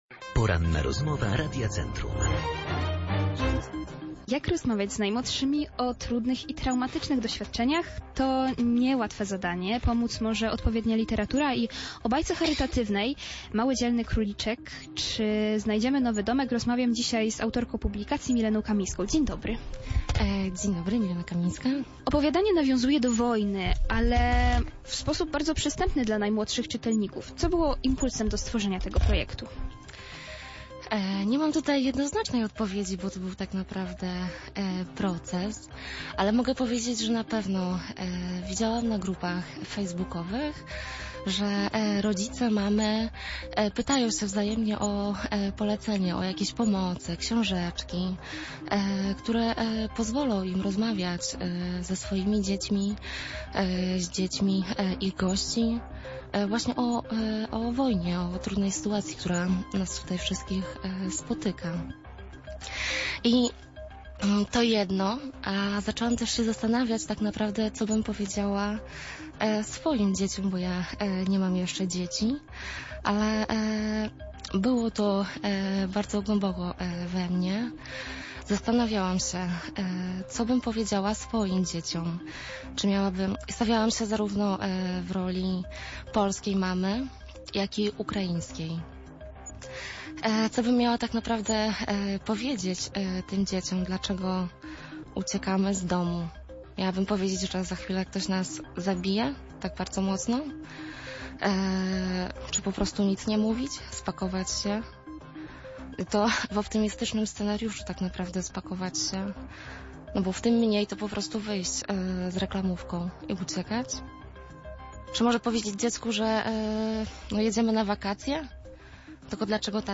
Opublikowano w Kultura, Poranna Rozmowa Radia Centrum